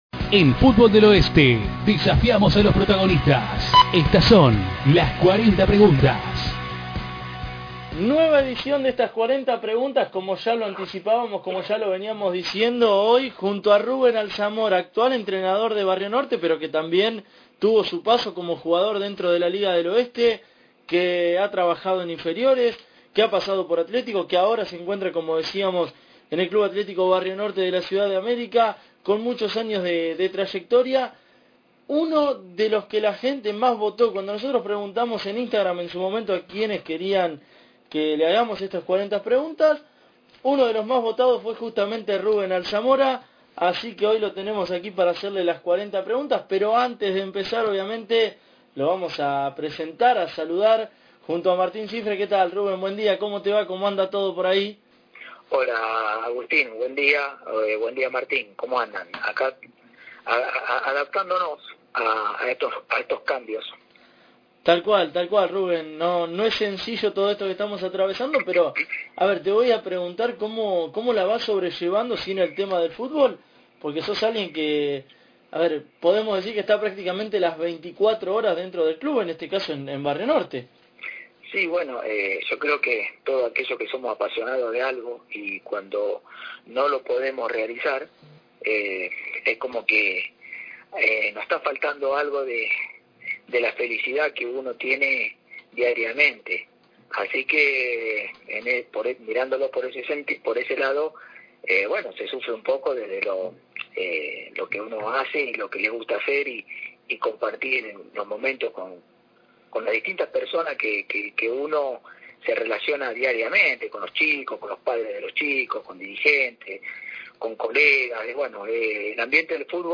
En este mano a mano